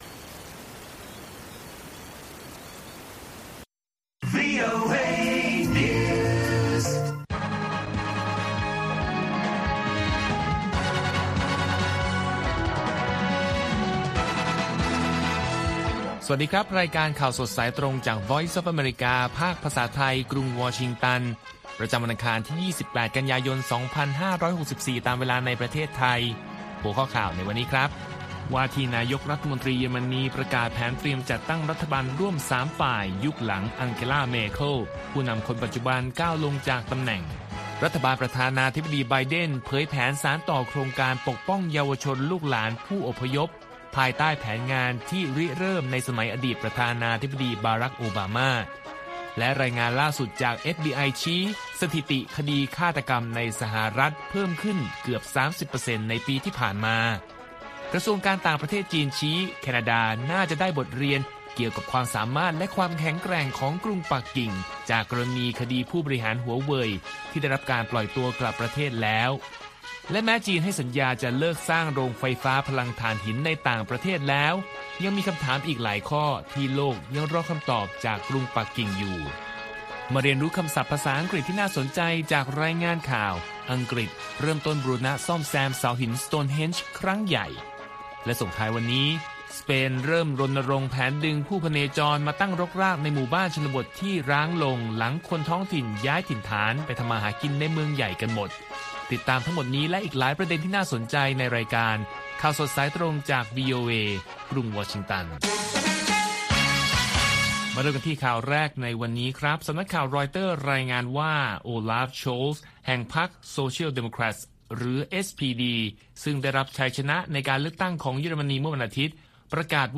ข่าวสดสายตรงจากวีโอเอ ภาคภาษาไทย ประจำวันอังคารที่ 28 กันยายน 2564 ตามเวลาประเทศไทย